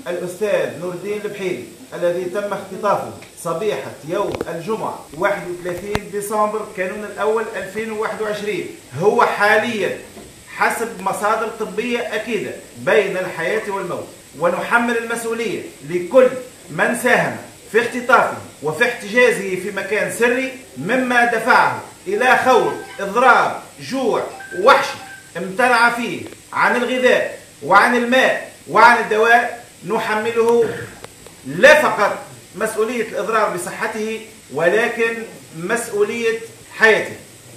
أعلن سمير ديلو خلال نقطة إعلامية نظمتها
تصريح-سمير-ديلو.wav